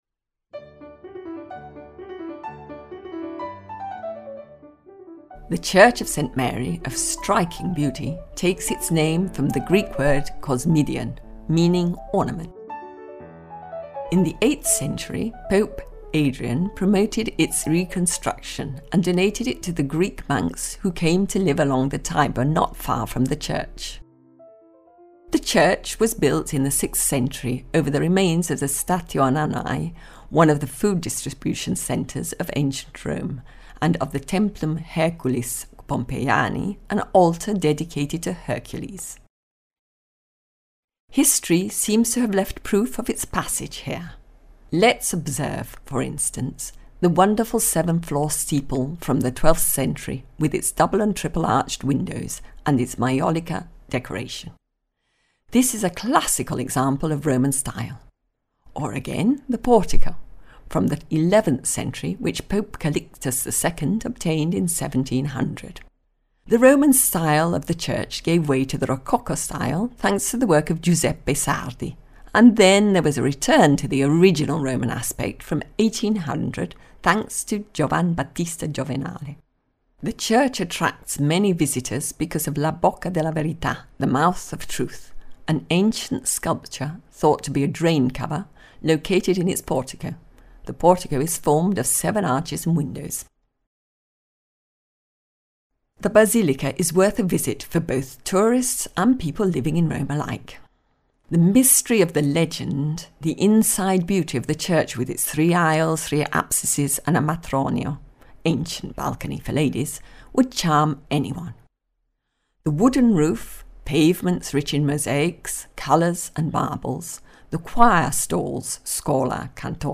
Audio Guide Rome – Saint Mary in Cosmedin